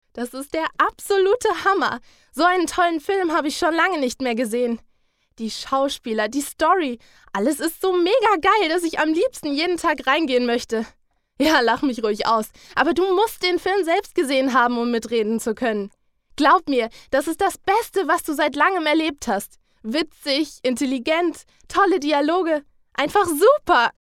Sprecherin deutsch
Kein Dialekt
Sprechprobe: Industrie (Muttersprache):
german female voice over artist